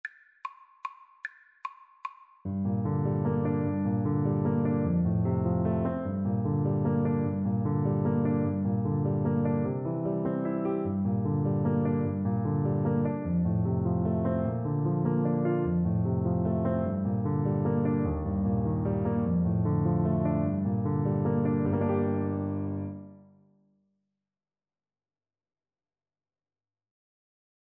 Play (or use space bar on your keyboard) Pause Music Playalong - Piano Accompaniment Playalong Band Accompaniment not yet available reset tempo print settings full screen
Tenor Saxophone
G minor (Sounding Pitch) A minor (Tenor Saxophone in Bb) (View more G minor Music for Tenor Saxophone )
3/4 (View more 3/4 Music)
Lento =150
Traditional (View more Traditional Tenor Saxophone Music)